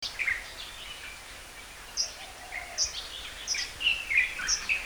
Calls
6 May 2012 Po Toi (1058)
A fast call with most energy centered around 6000 Hz is clearly a borealis call.